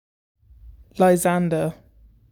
I’m from [name_f]England[/name_f], and I say Lie-SZAN-der. It sort of starts off as an S and goes into the Z (if that makes sense).
You have such a beautiful voice!